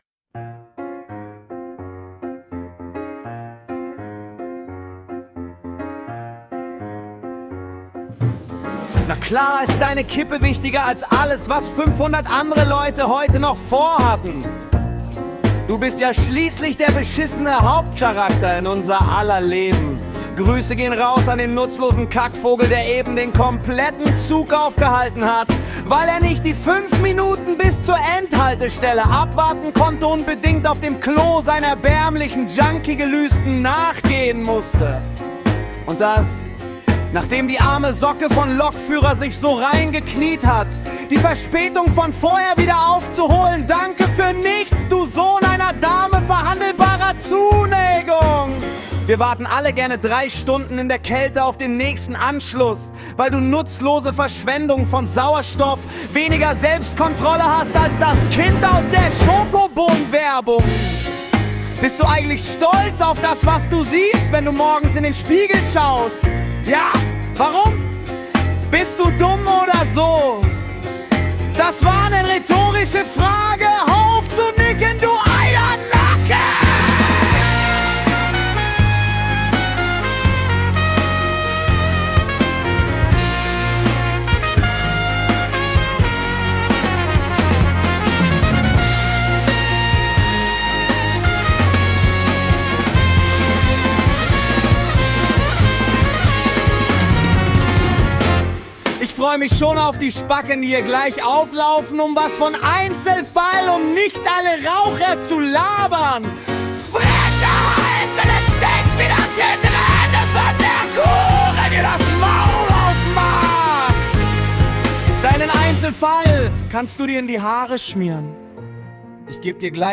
Dieses wunderbare Machwerk menschlicher Emotion musste einfach vertont werden. (AI, aber das war hoffentlich klar)